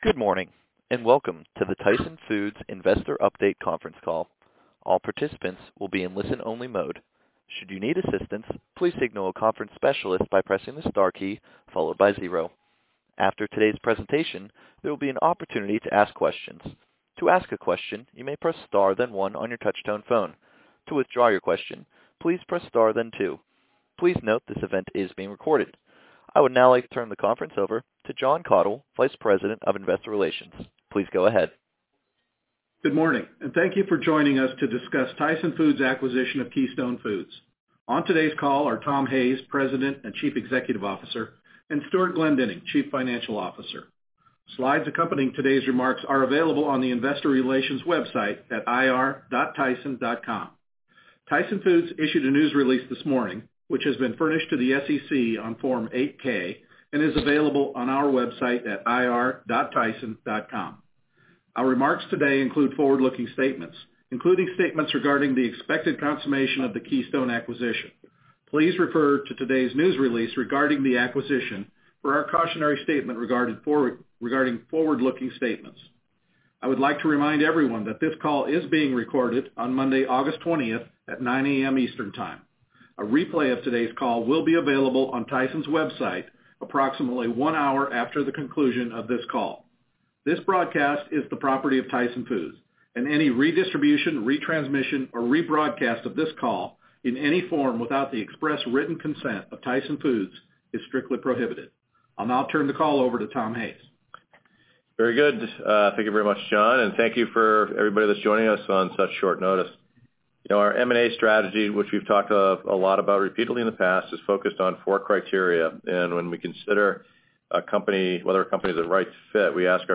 Tyson-Keystone-Acquisition-Call.mp3